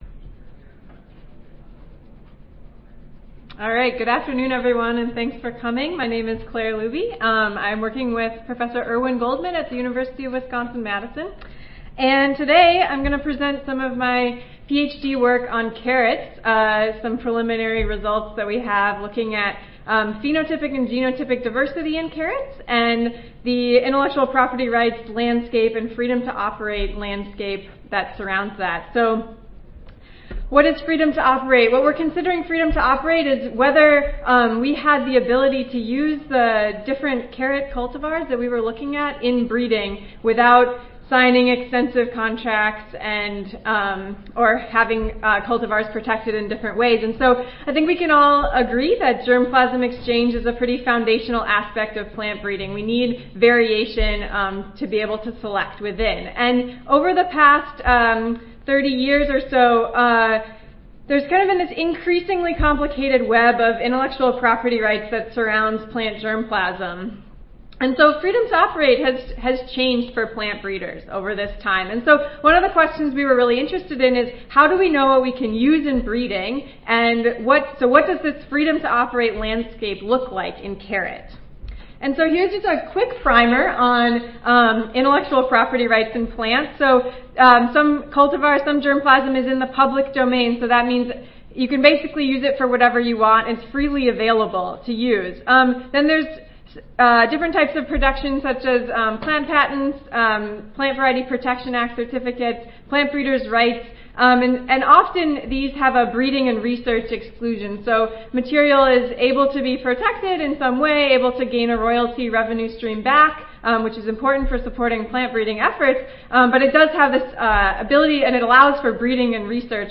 2015 ASHS Annual Conference: Vegetable Breeding 1 (Oral)
Recorded Presentation